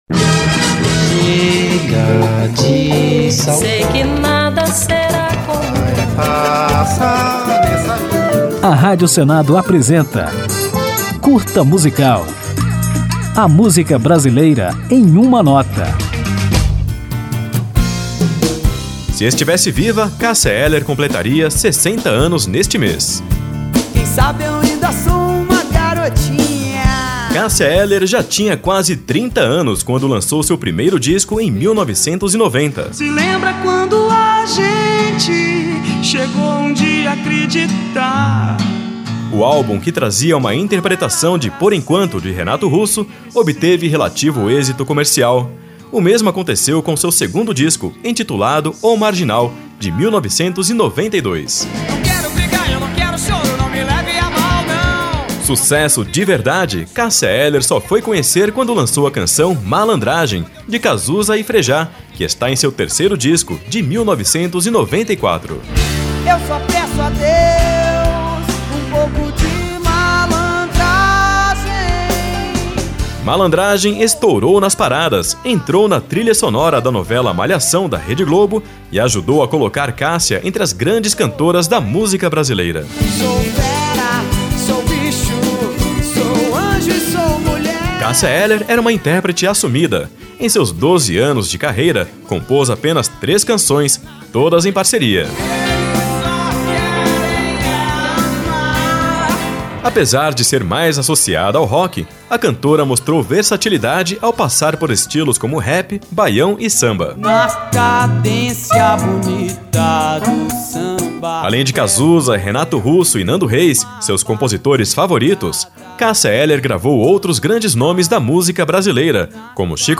Para homenageá-la, ouça este Curta Musical com um resumo da história da cantora, que termina ao som da gravação acústica do sucesso O Segundo Sol, lançada em 2001, meses antes da morte de Cássia Eller.